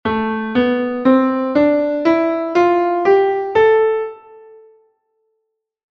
Escalas menores
Natural